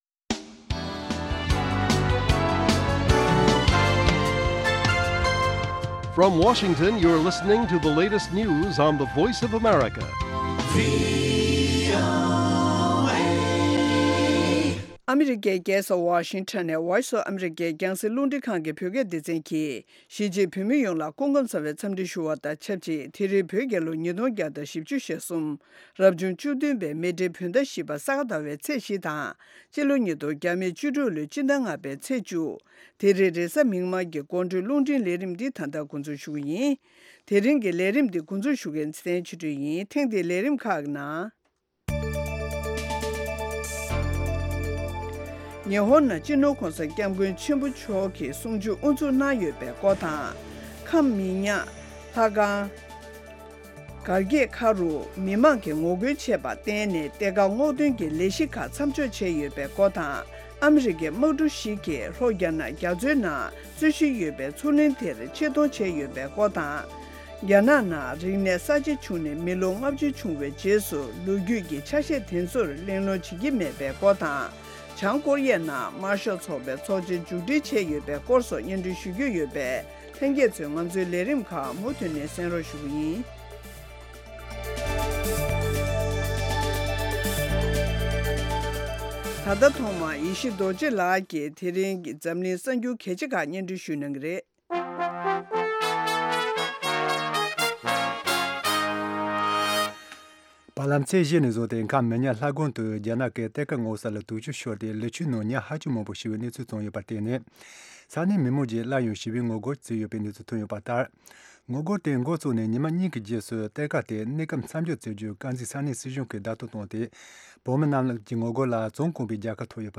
Evening News Evening News Broadcast daily at 10:00 PM Tibet time, the Evening Show presents the latest regional and world news, correspondent reports, and interviews with various newsmakers and on location informants. Weekly features include Tibetan Current Affairs, Youth, Health, Buddhism and Culture, and shows on traditional and contemporary Tibetan music.